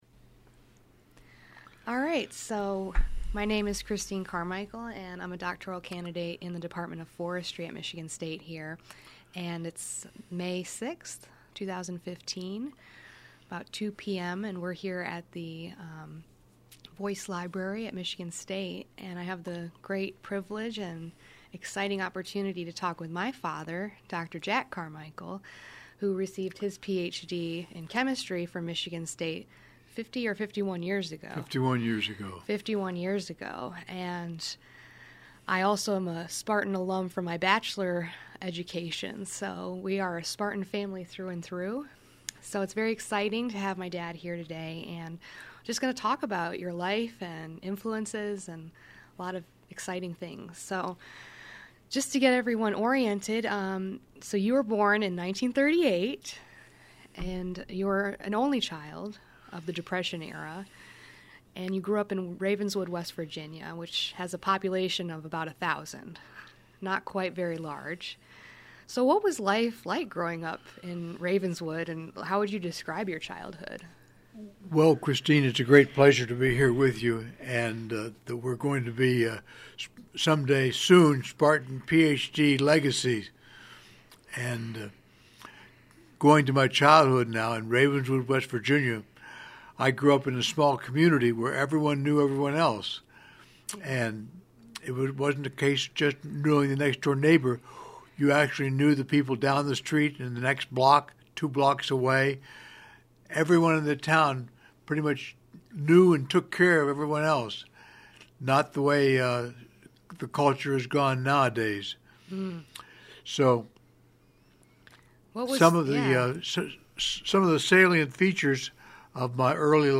Michigan State University alumnus and noted chemist and expert in environmental pollution studies and industrial development discusses his life and career in a wide-ranging oral history interview